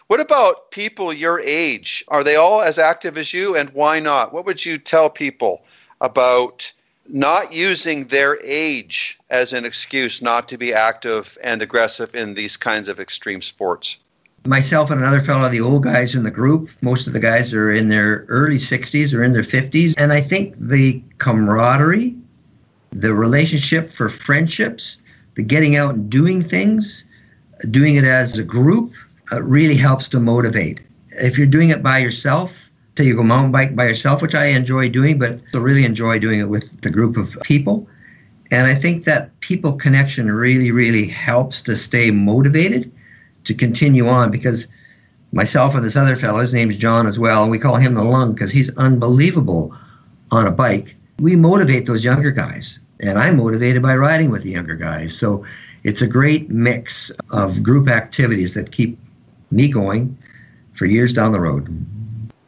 Special Guest Interview Volume 17 Number 10 V17N10c